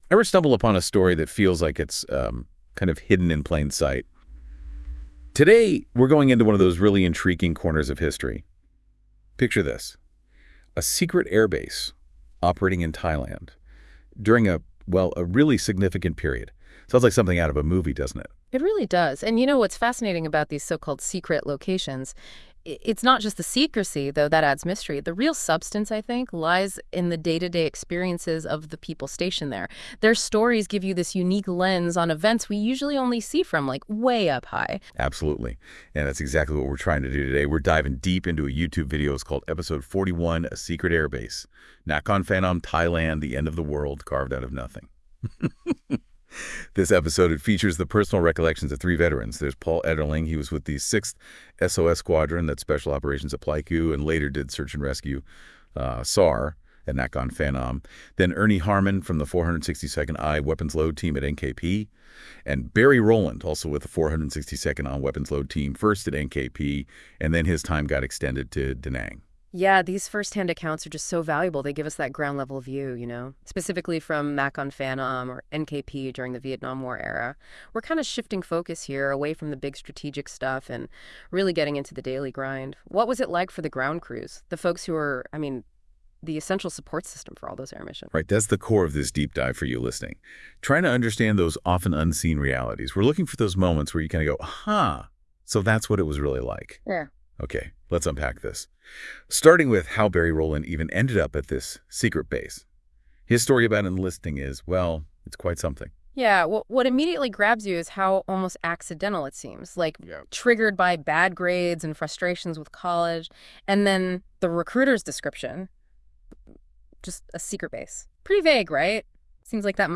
This episode features a conversation with three veterans who served at Nakhon Phanom (NKP) Royal Thai Air Base